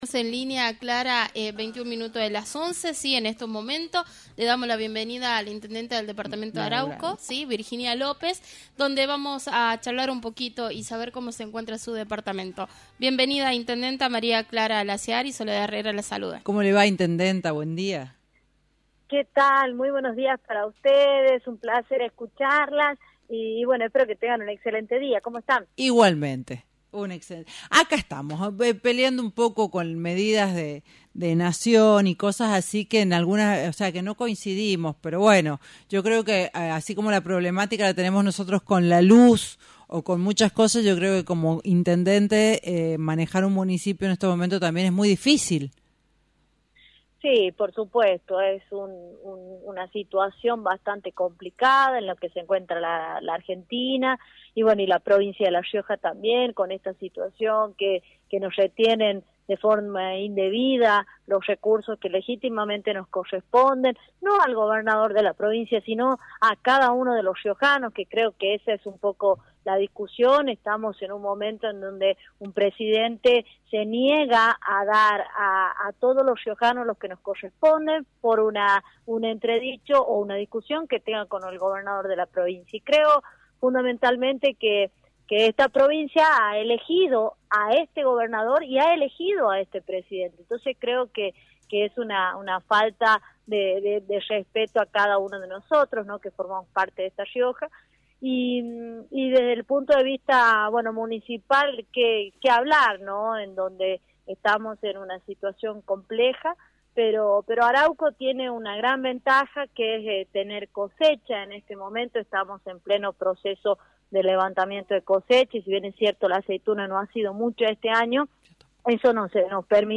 La Jefa comunal, Virginia López, fue entrevistada en Radio Libertad y se refirió a la actual situación que viven las provincias taras las medidas que está adoptando el gobierno nacional.